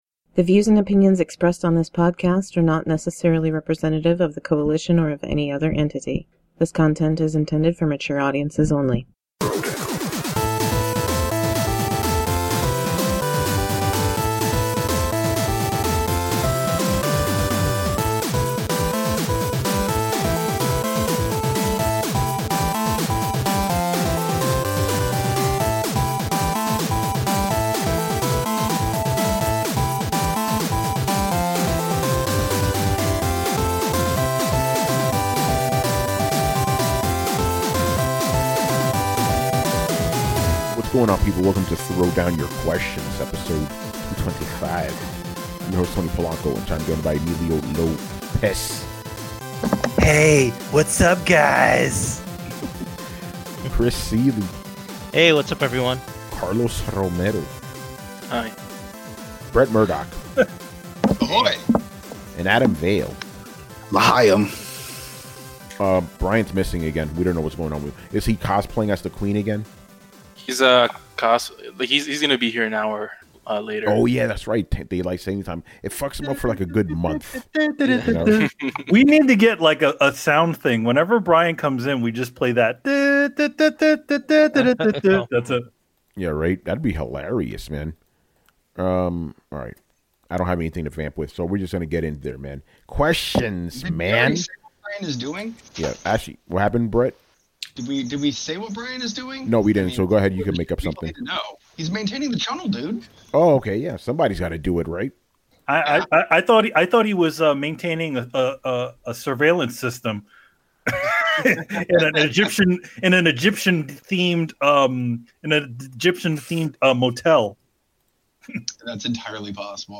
Follow the panelists on Twitter